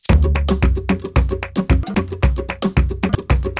133SHAK01.wav